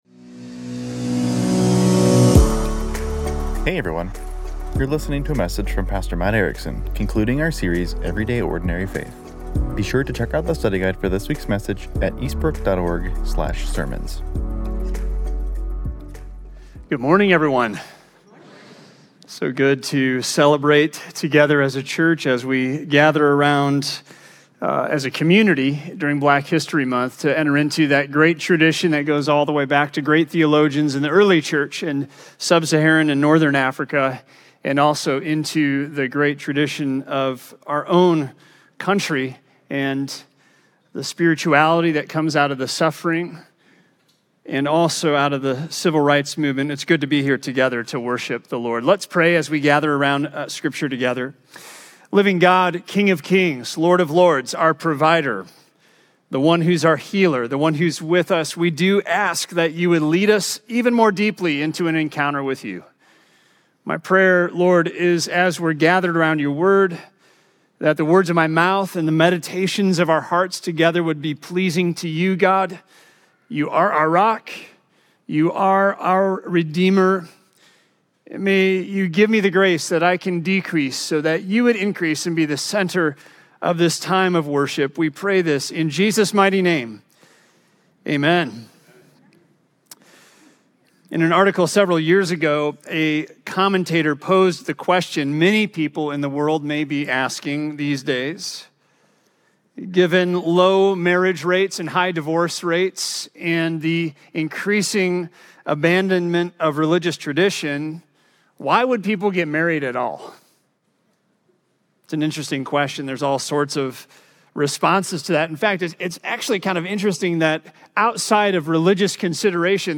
This week we conclude our sermon series entitled “Everyday Ordinary Faith” by exploring the topic of faith-shaped marriage.